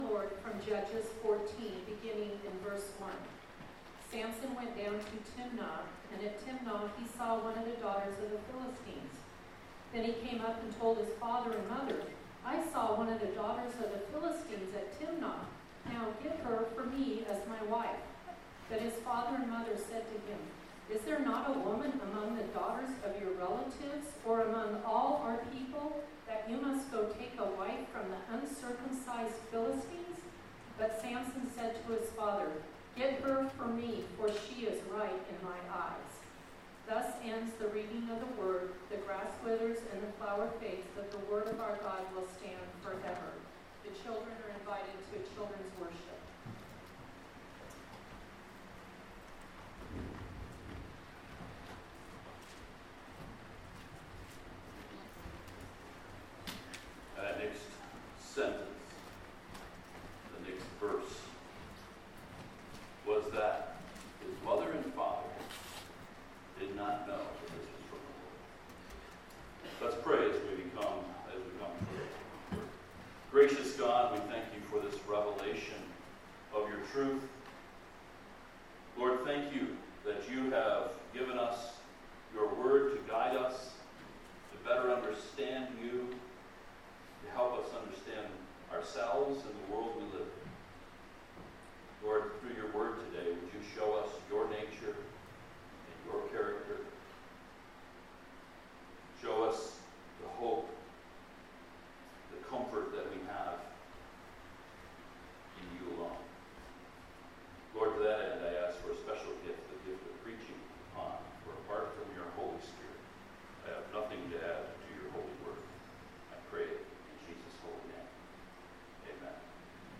Judges 13-14: Samson,God’s Rogue Warrior(7-7-24) | Sunrise Church McMinnville, OR